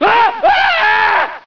lots of screaming scientists
scream4.ogg